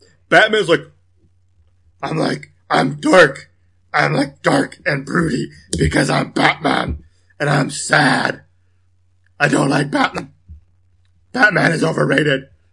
Tag: 130 bpm Weird Loops Fx Loops 1.35 MB wav Key : Unknown